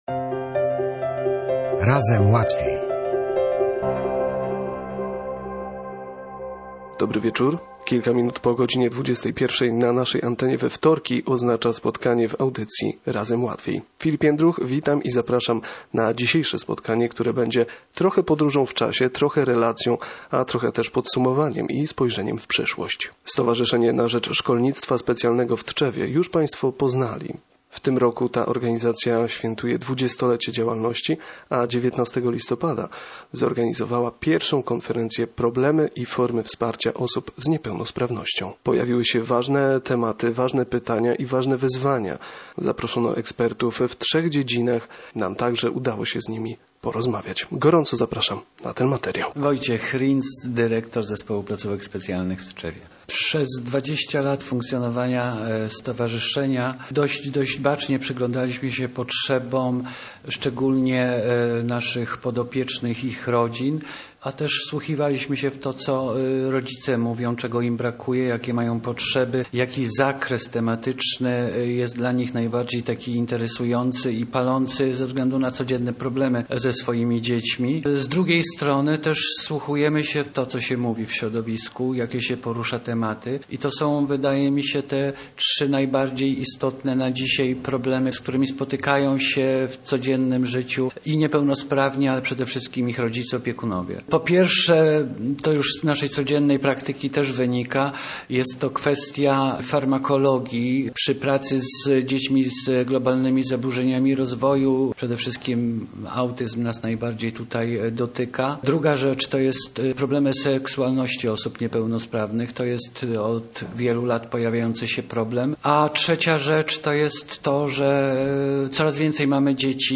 Zachęcamy do odsłuchania materiału, który jest jednocześnie relacją i omówieniem kwestii poruszonych podczas konferencji, a także spojrzeniem na przyszłe inicjatywy Stowarzyszenia Na Rzecz Szkolnictwa Specjalnego w Tczewie.